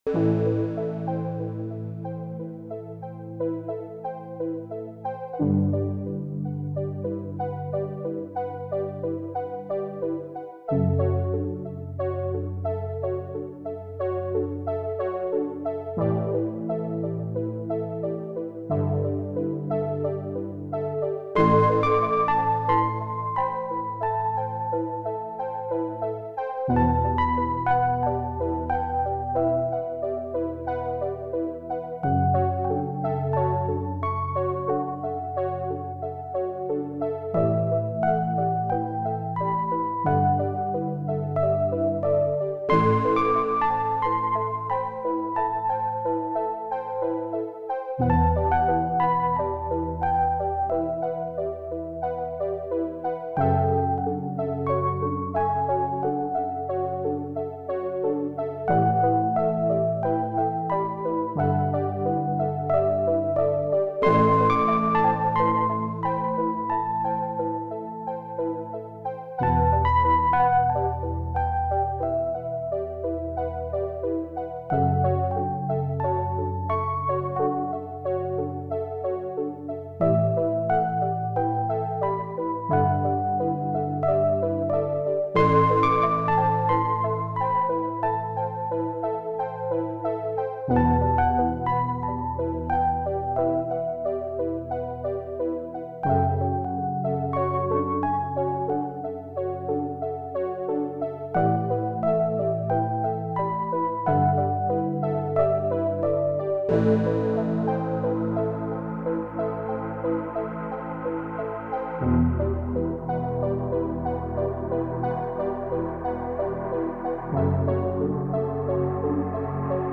Filed under: Instrumental | Comments (2)
In any event, I thought this piece felt loopy by the time i got to the end, which doesn't make sense for a movie soundtrack.